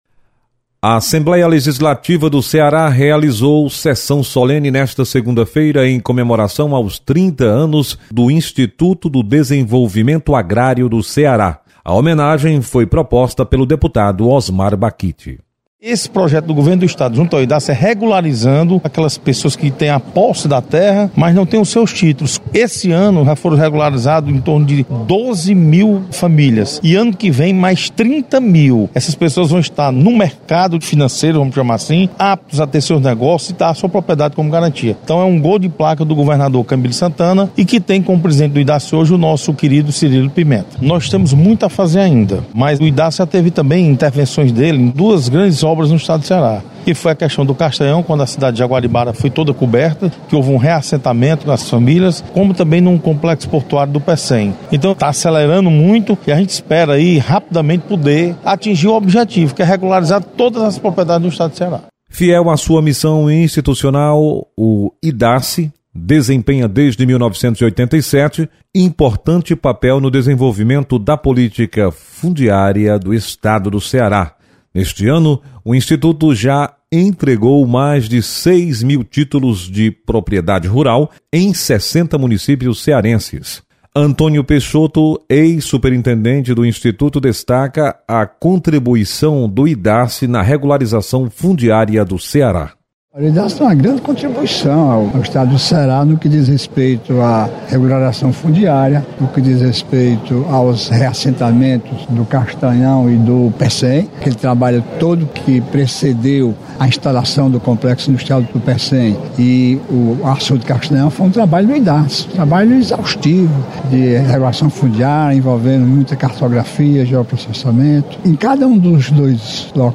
Sessão solene